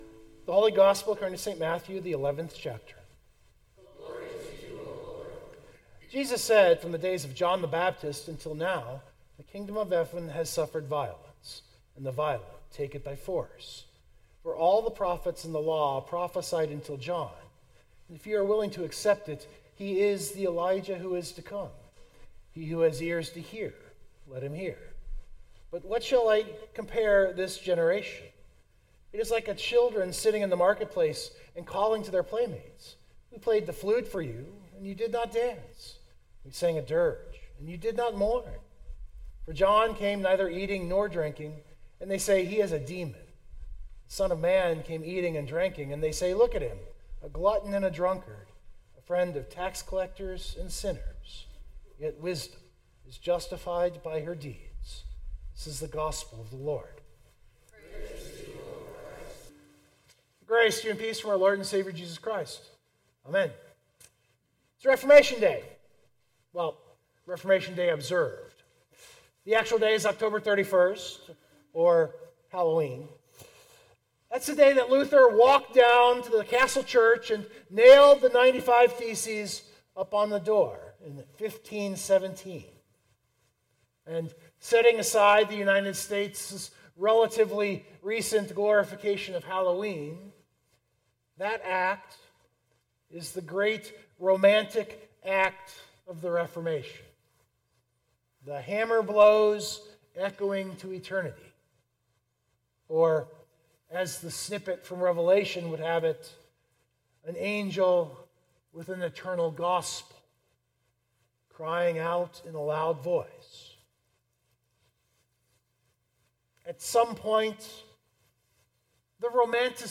060224-Sermon Download Biblical Text